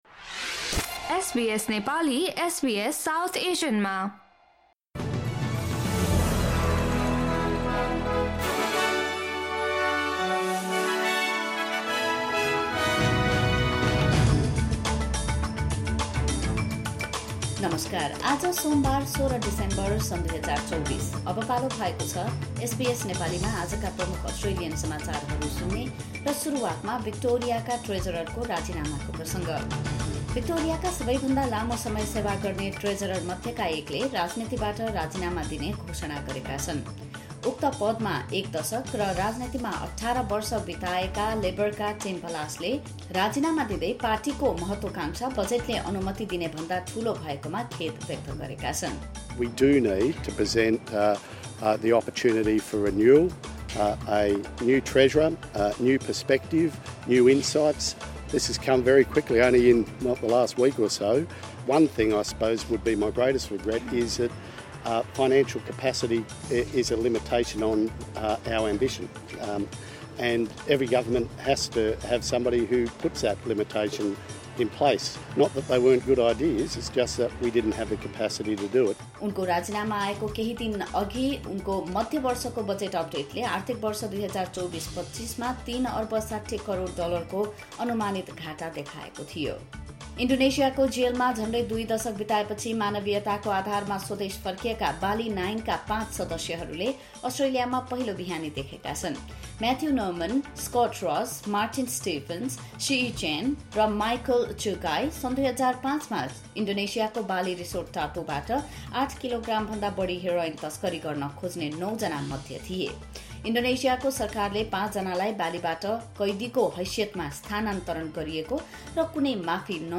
SBS Nepali Australian News Headlines: Monday, 16 December 2024